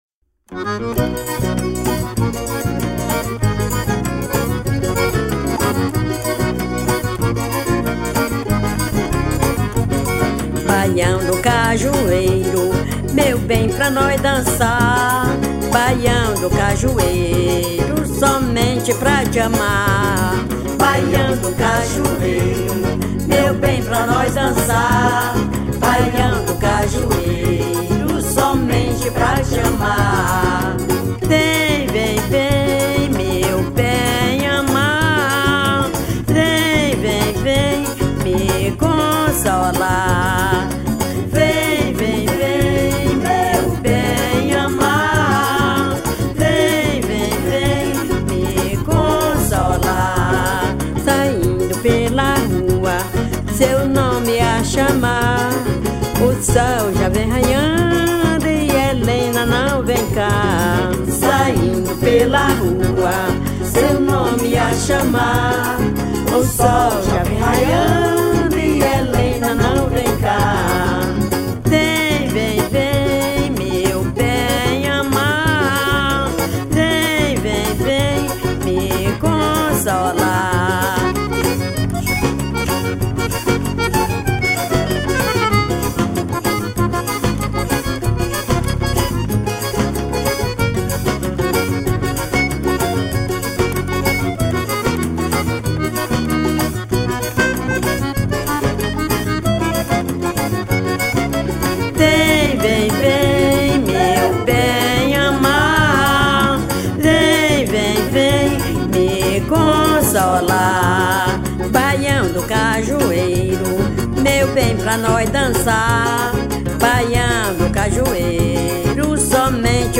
125   03:23:00   Faixa:     Forró
Acoordeon
Baixo Elétrico 6
Cavaquinho, Violao 7
Percussão